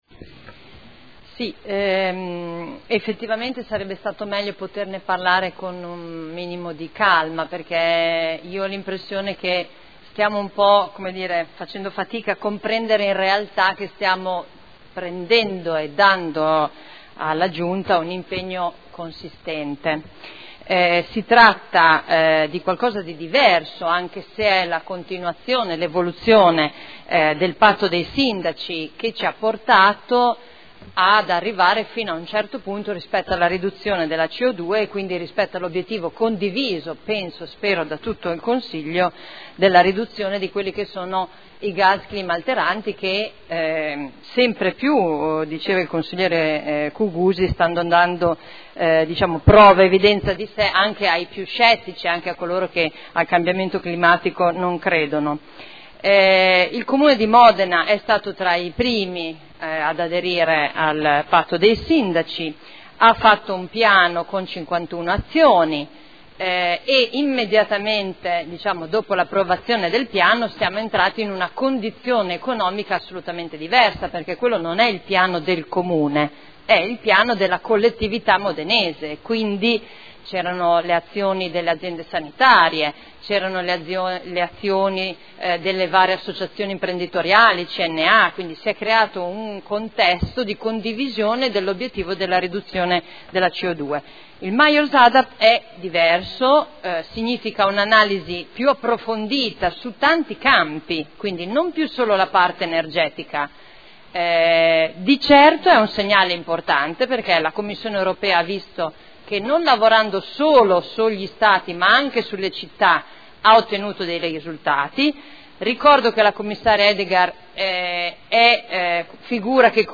Seduta del 16 ottobre. Ordine del giorno: Adesione del comune di modena alla iniziativa della commissione europea a “Mayors Adapt”. Dibattito